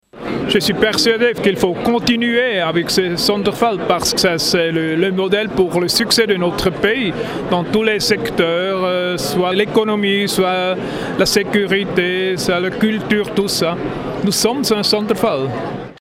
Ueli Maurer, ministre UDC (droite conservatrice) de la Défense